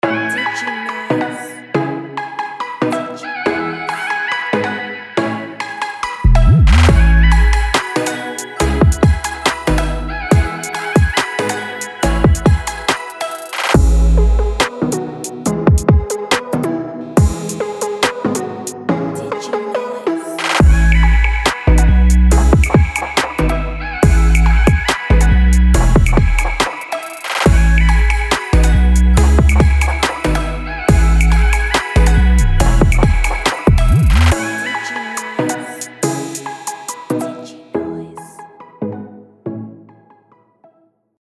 Hip-Hop, Trap, R&B, Pop or even cinematic scoring.